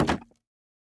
Minecraft Version Minecraft Version 1.21.5 Latest Release | Latest Snapshot 1.21.5 / assets / minecraft / sounds / block / bamboo_wood_trapdoor / toggle1.ogg Compare With Compare With Latest Release | Latest Snapshot